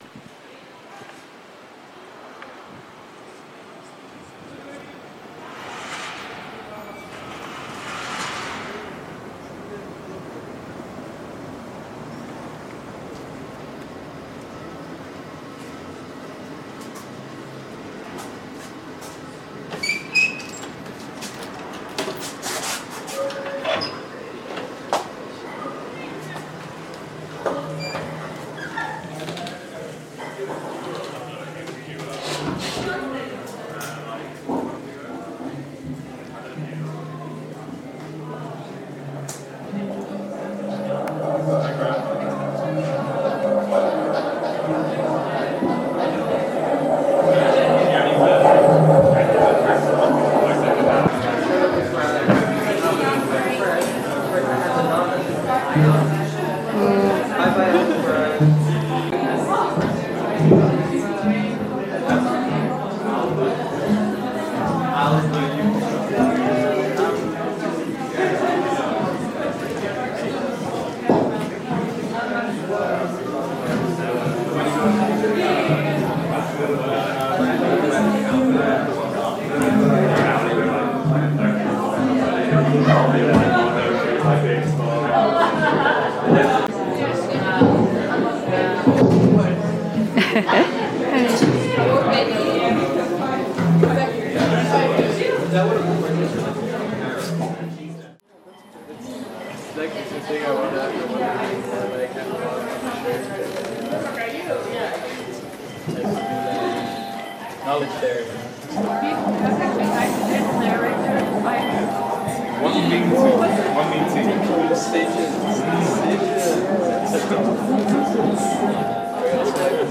Room_noise-all_put_together.mp3